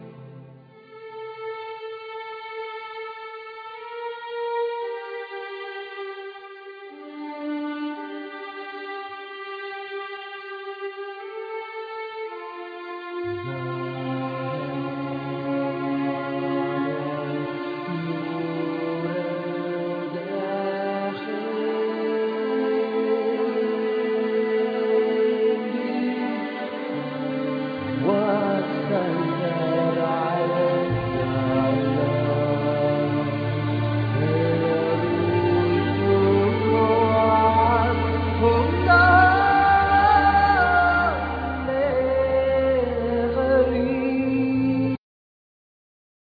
Piano,Synthe Solo
Lyricon
Bandoneon
Ney
Drums
Bass
Percussion,Vocal